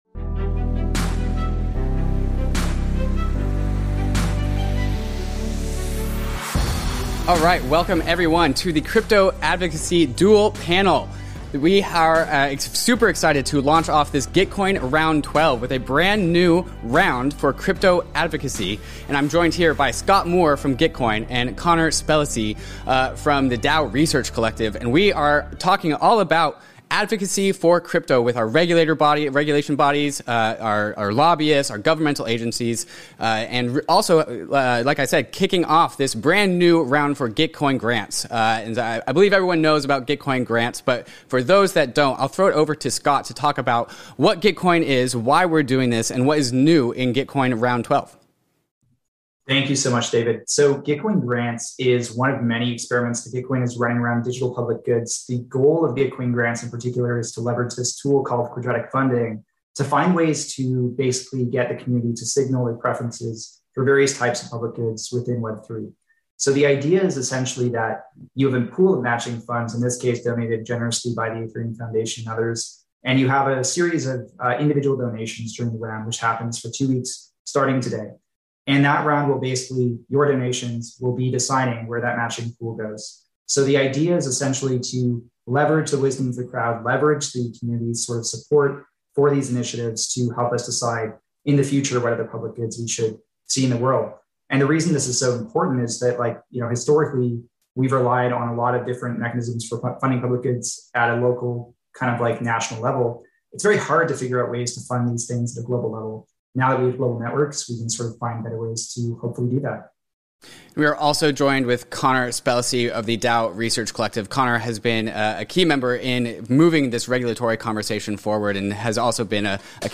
Crypto Advocacy Panel